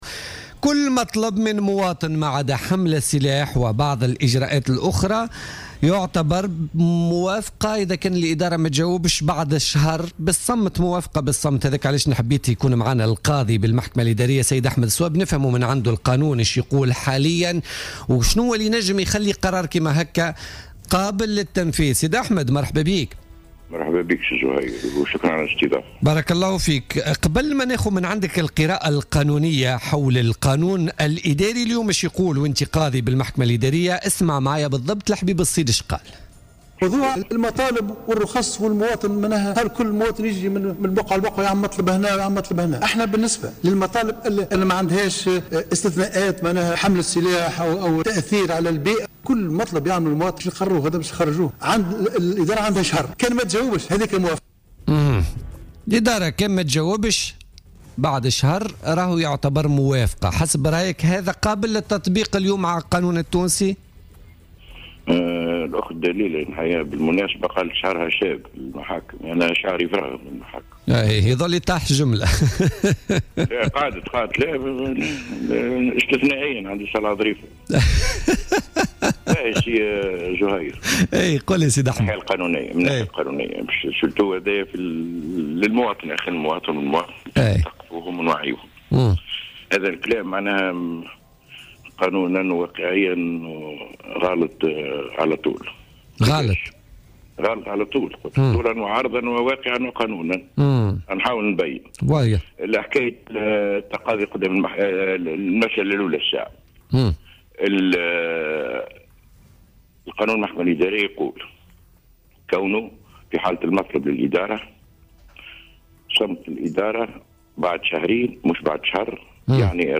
أكد القاضي بالمحكمة الإدارية أحمد صواب في مداخلة له في بوليتيكا اليوم الجمعة 29 جانفي 2016 أن القرار الذي أعلن عنه رئيس الحكومة الحبيب الصيد أمس والمتعلق بأن كل مطلب من مواطن لم تجب عليه الإدارة في ظرف شهر يعد مقبولا غير ممكن قانونا وواقعا على حد قوله.